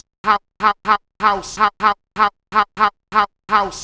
cch_vocal_haus_125.wav